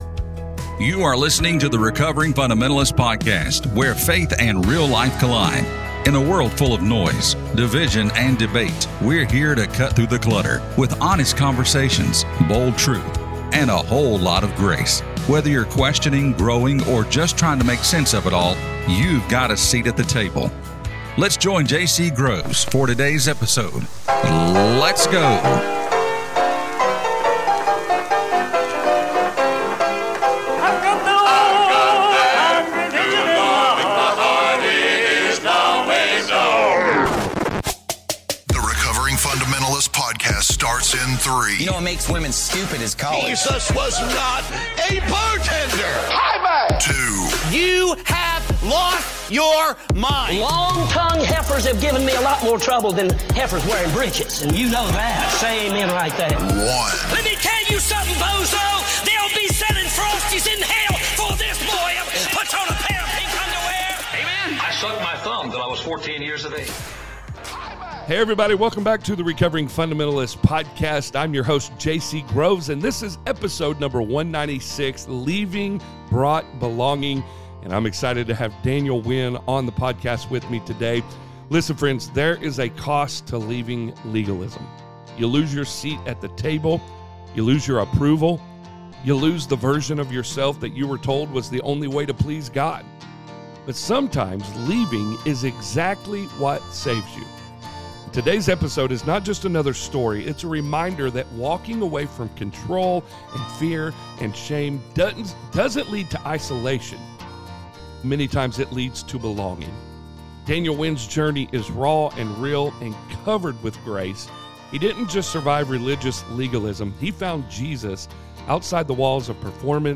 Don't miss this heartfelt conversation that reminds us all of the beauty found in leaving and the belonging that follows.